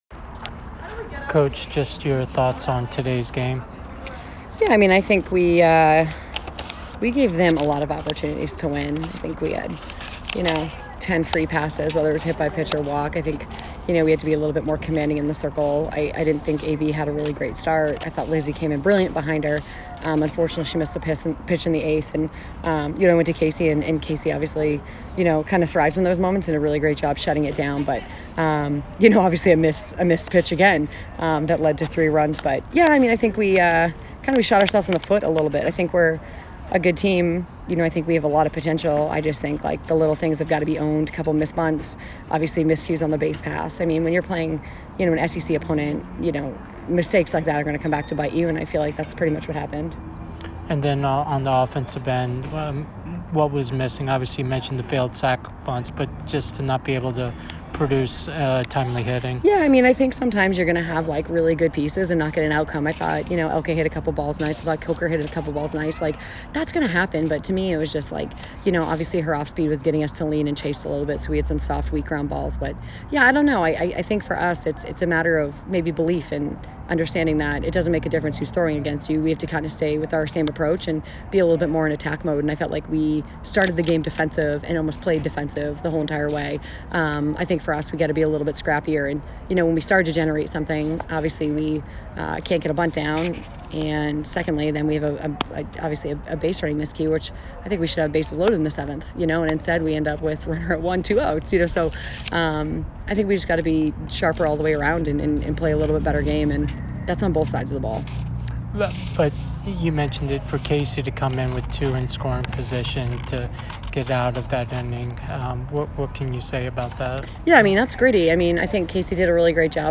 South Carolina Postgame Interview